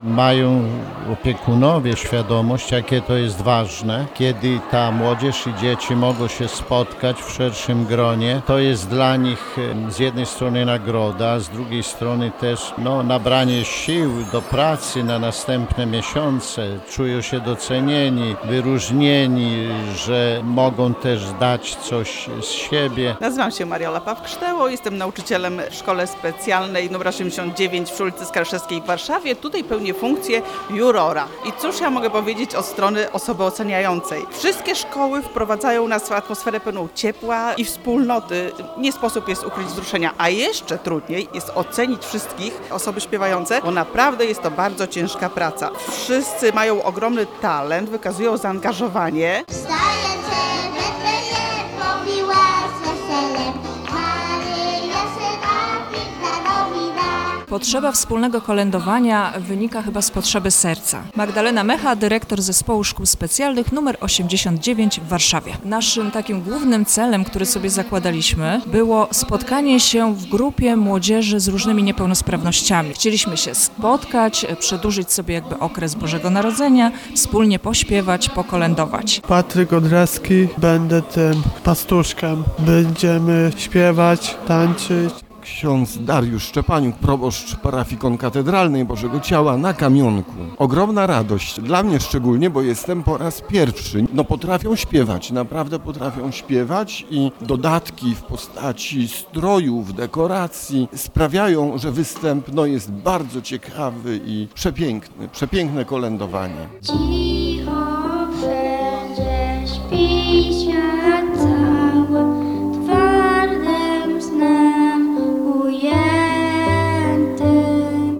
„Od serca do ucha, kolędujemy na ludowo” – to hasło III Wojewódzkiego Przeglądu Kolęd i Pastorałek, który odbywa się dziś w Centrum Promocji Kultury na Pradze – Południe.
Biskup warszawsko – praski Romuald Kamiński mówił, że spotkanie pełni szczególnie ważną rolę.